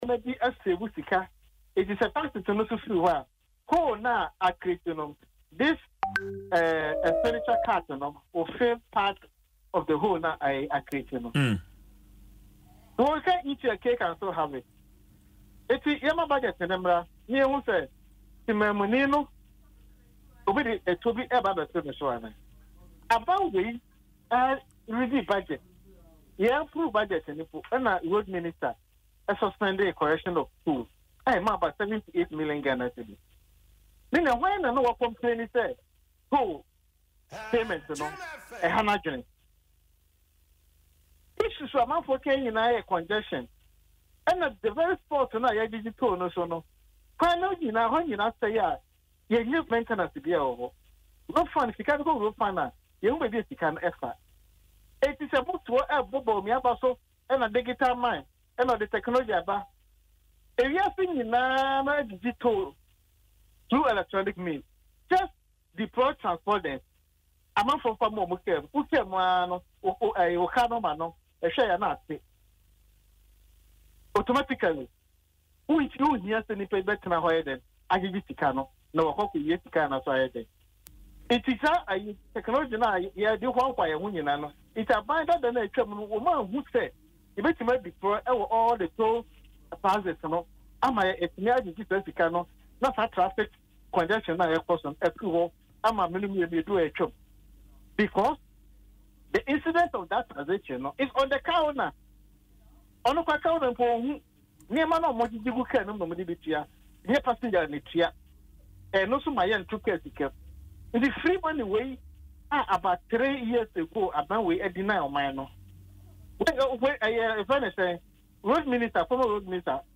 Speaking in an interview on Adom FM’s Dwaso Nsem, he questioned why such a critical revenue stream was suspended without viable alternatives.